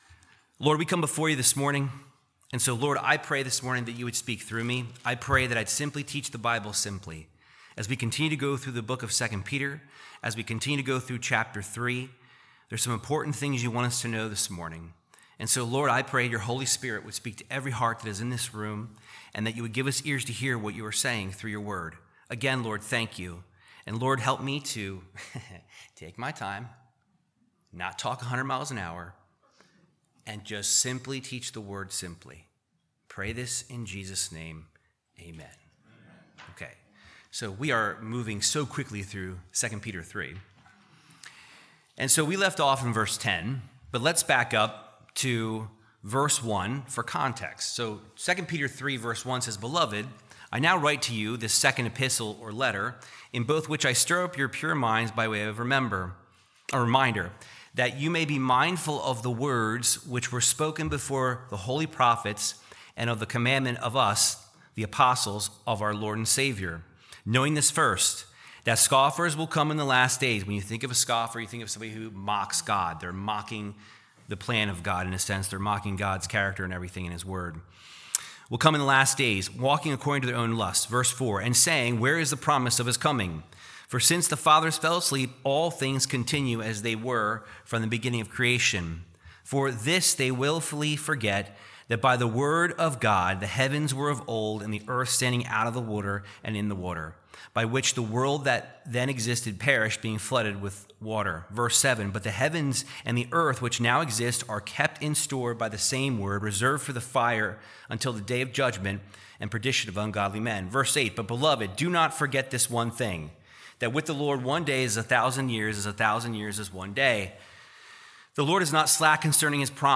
Verse by verse Bible Teaching of 2 Peter 3:10-13